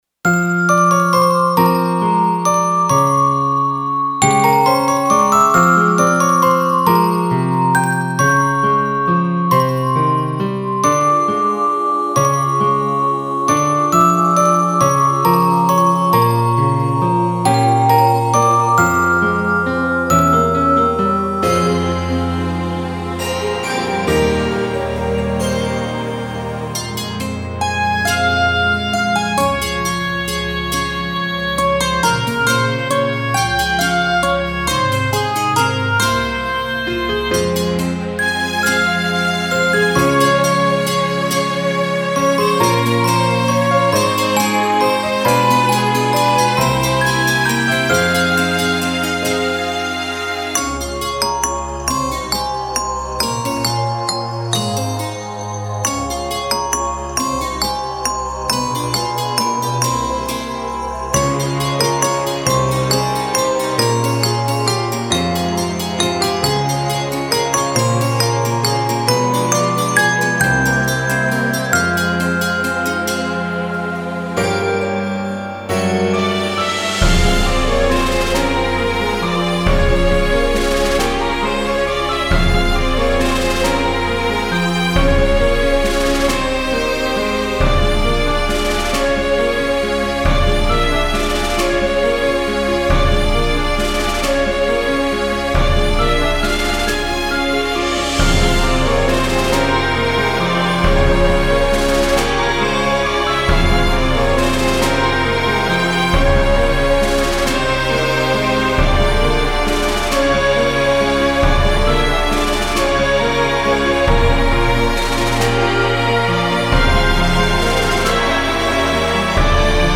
フリーBGM イベントシーン ホラー・不気味・不穏
フェードアウト版のmp3を、こちらのページにて無料で配布しています。